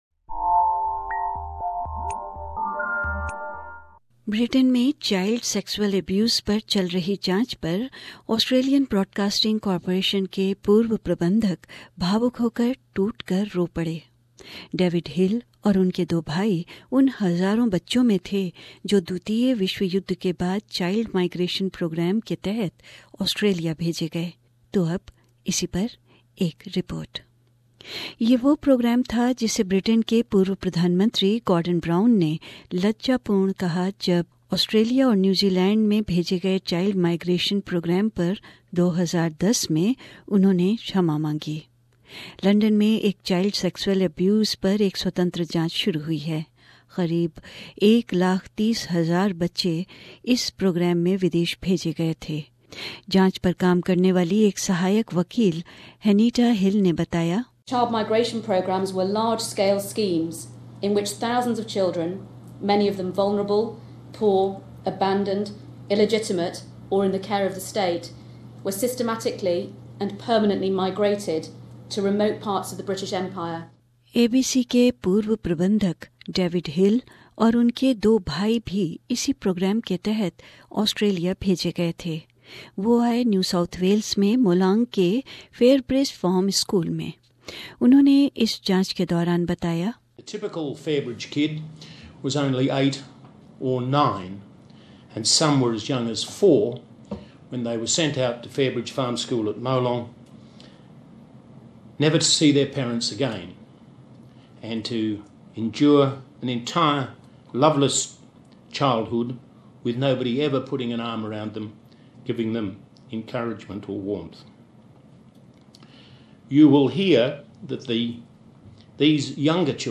Former managing director of the Australian Broadcasting Corporation, Mr. David Hill has broken down while addressing an inquiry in Britain into historic cases of child sexual abuse. A report